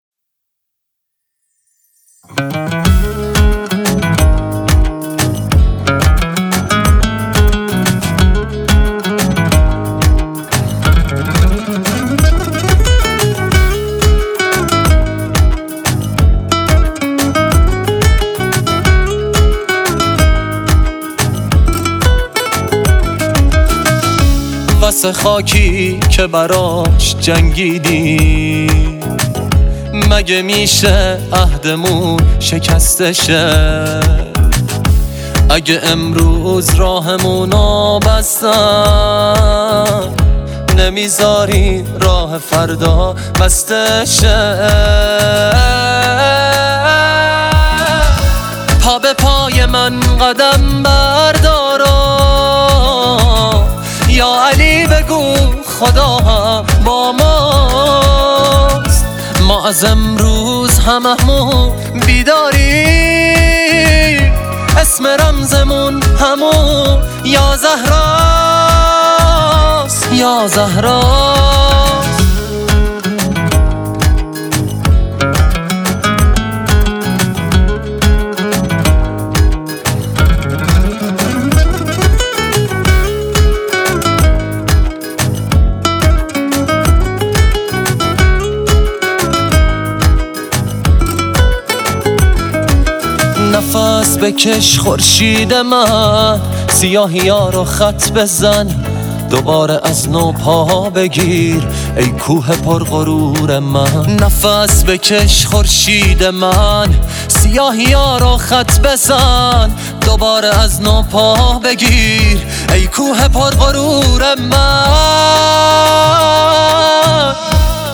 آهنگ حمایت از جلیلی